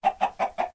assets / minecraft / sounds / mob / chicken / say2.ogg